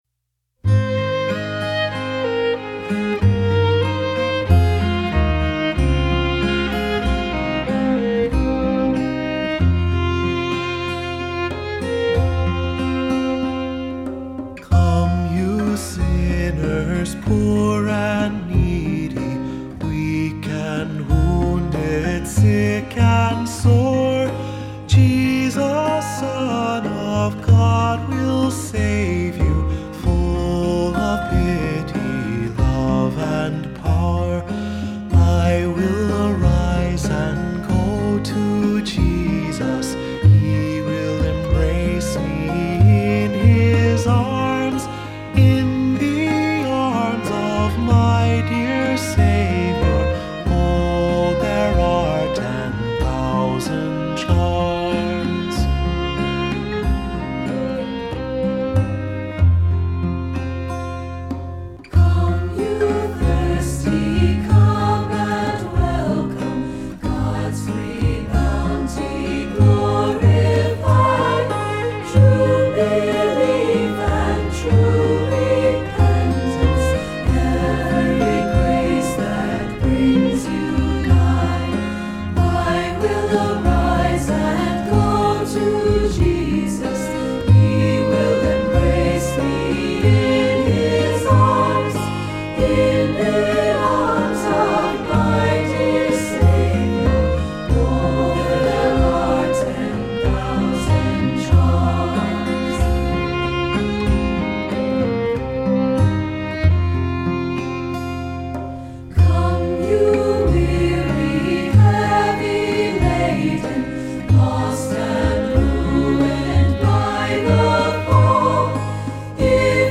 Accompaniment:      Piano
Music Category:      Christian